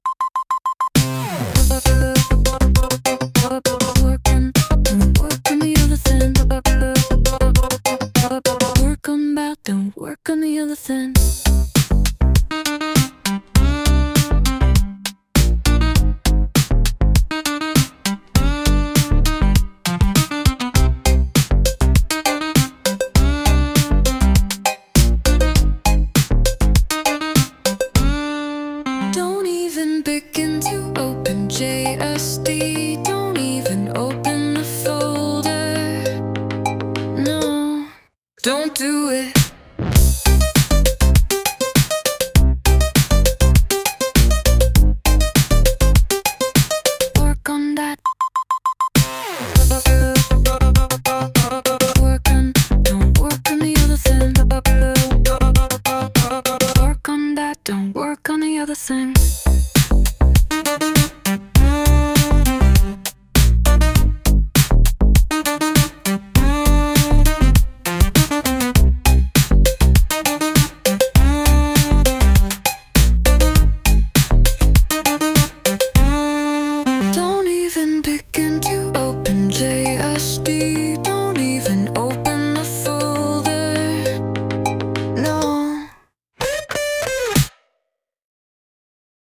This version missed about 90% of the lyrics.
Sung by Suno